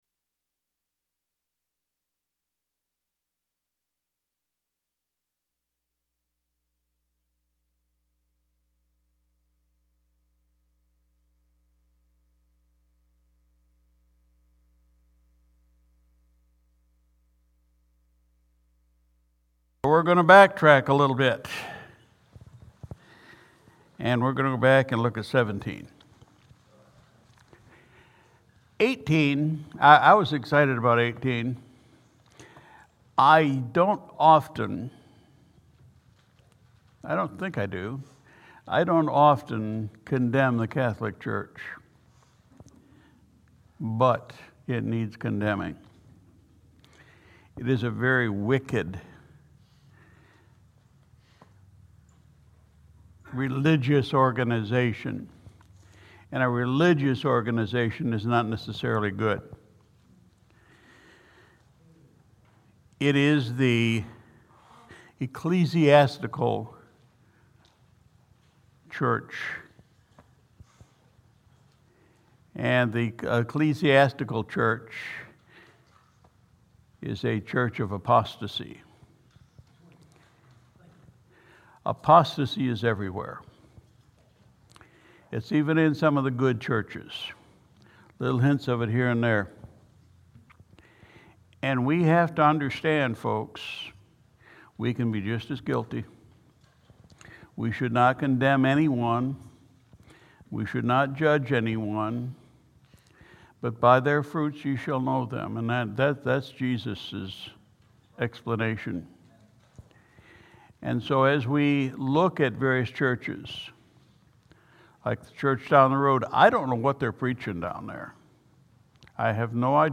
May 1, 2022 Sunday Evening Service We continued our study in the Book of Revelation 17:1-18